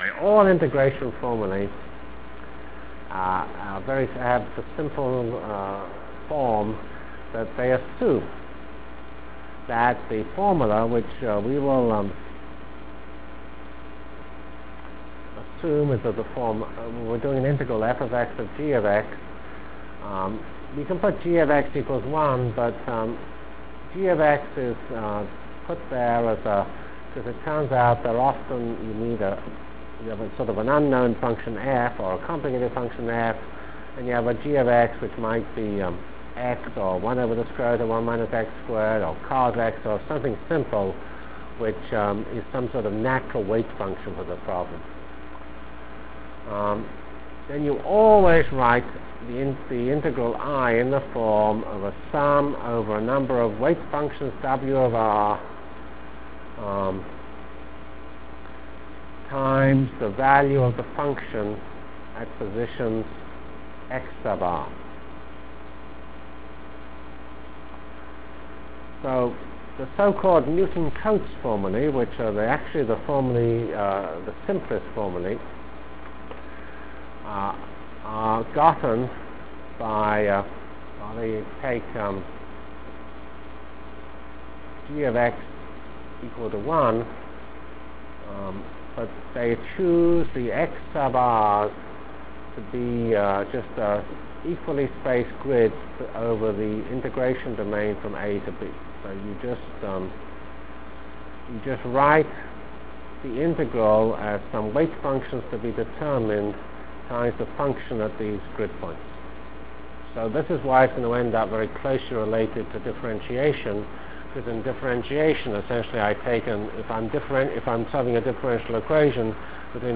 From CPS615-End of N-Body Discussion and Beginning of Numerical Integration Delivered Lectures of CPS615 Basic Simulation Track for Computational Science -- 15 October 96. *